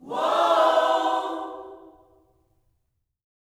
WHOA-OHS 9.wav